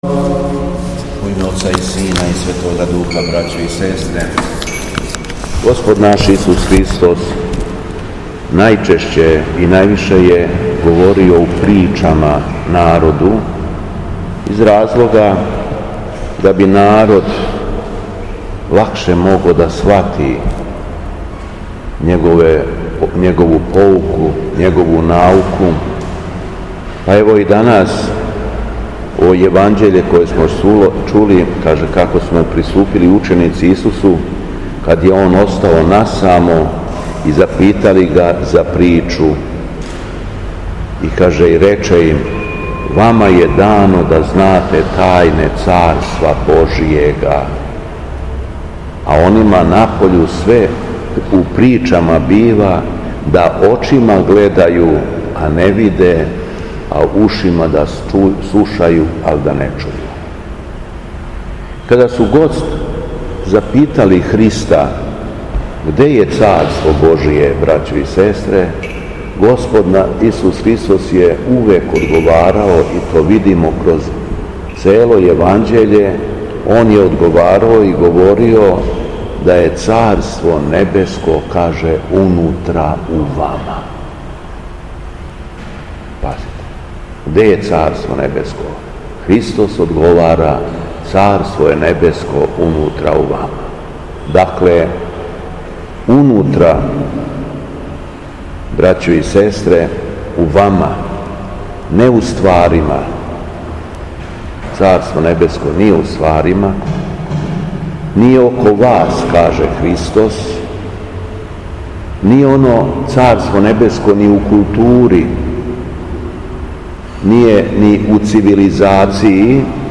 У понедељак четрнаести по Духовима, када наша света Црква прославља светог мученика Агатоника, Његово Преосвештенство Епископ шумадијски Господин Јован служио је свету архијерејску литургију у храму Светога Саве у крагујевачком насељу Аеродром.
Беседа Његовог Преосвештенства Епископа шумадијског г. Јована